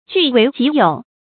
據為己有 注音： ㄐㄨˋ ㄨㄟˊ ㄐㄧˇ ㄧㄡˇ 讀音讀法： 意思解釋： 據：占據。